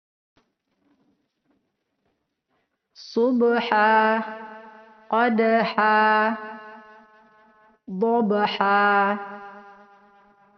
Panjangnya dibaca 2 harokat.